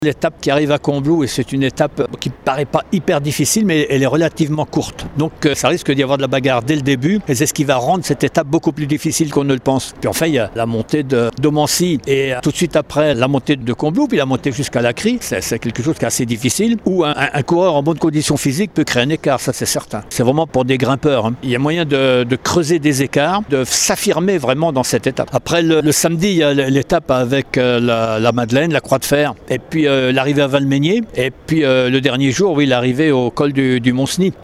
Autant d’étapes qui devraient permettre aux grimpeurs de s’affirmer, et ça commencera donc dès vendredi. Le point avec Bernard Thévenet, directeur du critérium du Dauphiné, ancien double vainqueur du tour de France